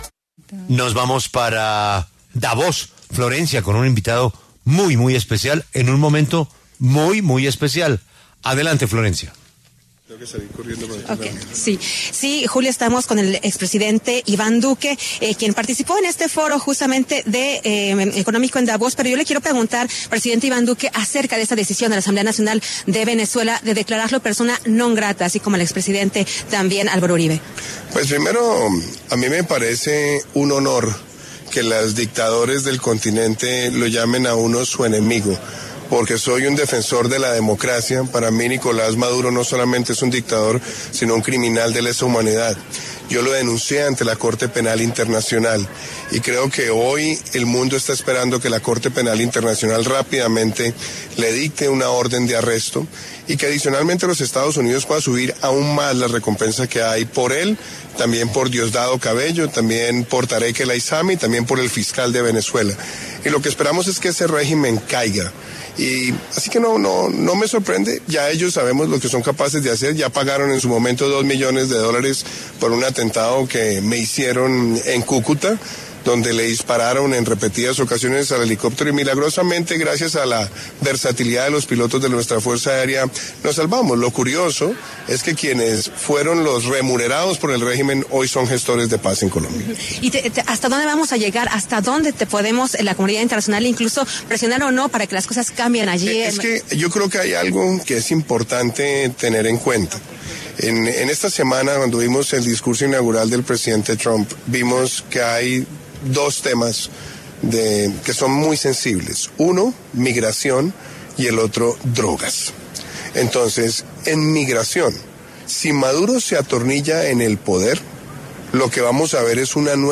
El expresidente Iván Duque, pasó por los micrófonos de La W y aseguró que hay que hacer caer al régimen de Nicolás Maduro.
El expresidente Iván Duque, en el marco del Foro de Davos, pasó por los micrófonos de La W y habló sobre la decisión de la Asamblea Nacional de Venezuela al declararlo persona non-grata, como también sucedió con Álvaro Uribe.